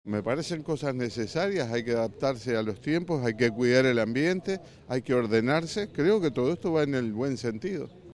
un vecino de la zona